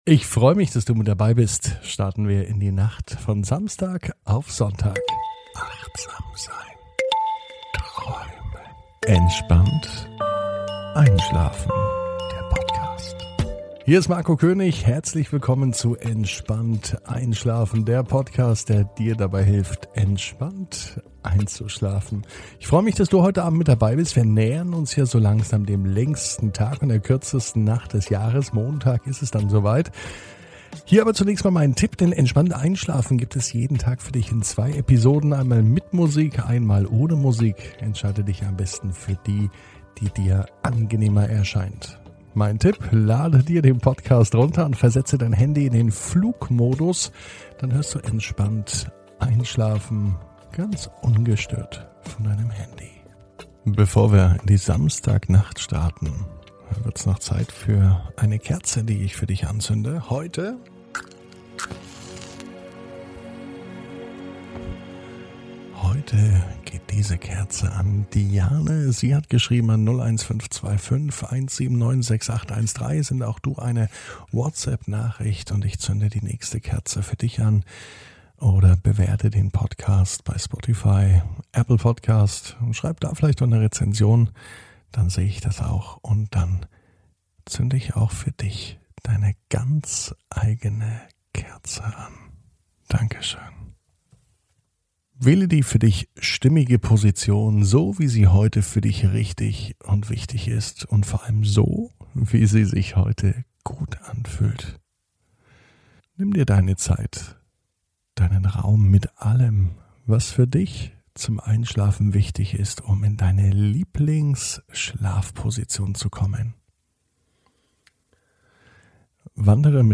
(ohne Musik) Entspannt einschlafen am Samstag, 19.06.21 ~ Entspannt einschlafen - Meditation & Achtsamkeit für die Nacht Podcast